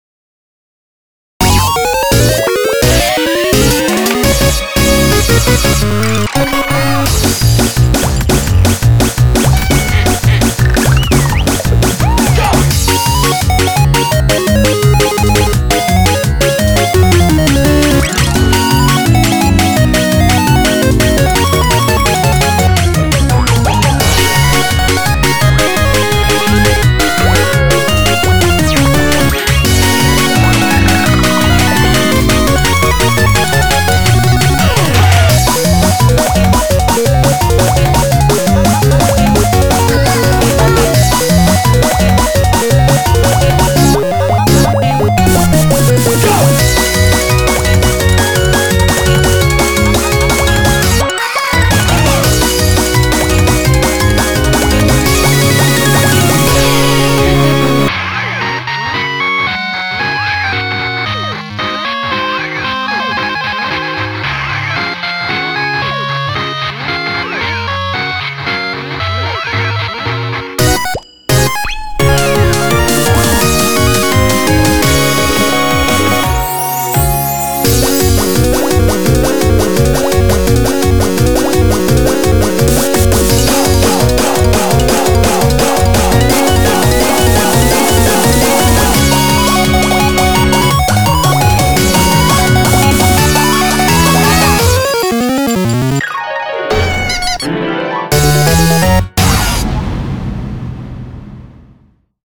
BPM113-170
Audio QualityPerfect (High Quality)